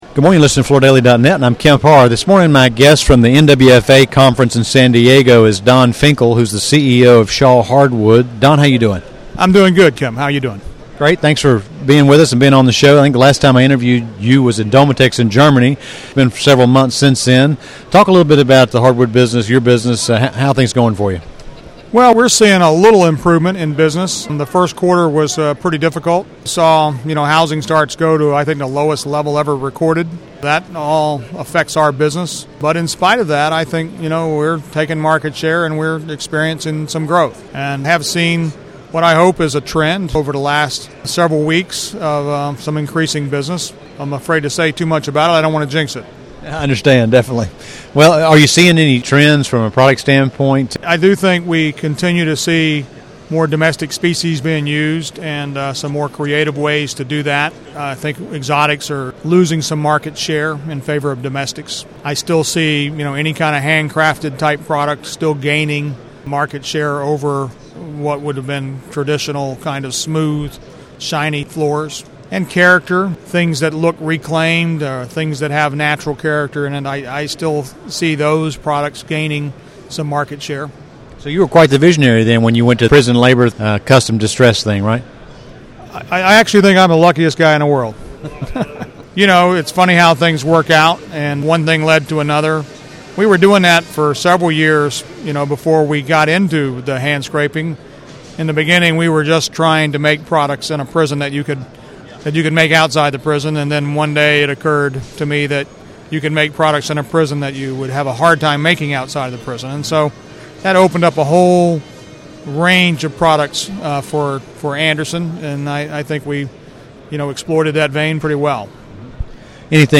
Listen to this interview which was recorded last week at the NWFA show in San Diego